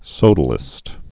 (sōdl-ĭst, sō-dălĭst)